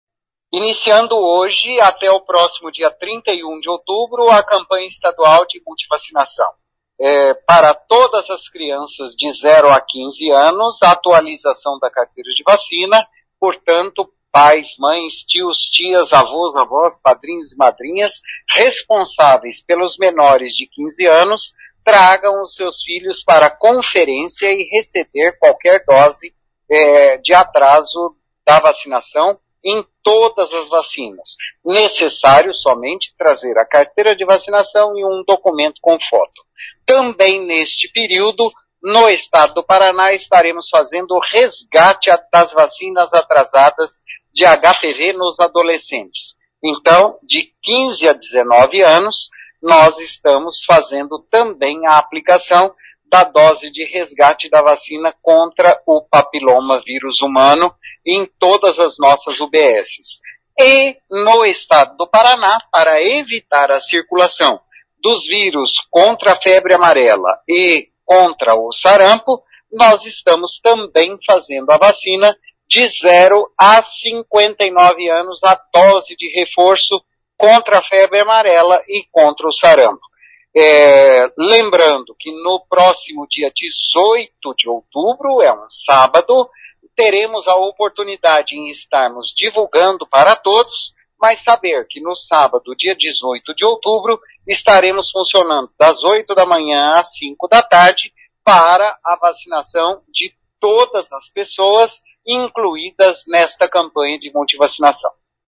Ouça o que diz o secretário de Saúde de Maringá, Antônio Carlos Nardi.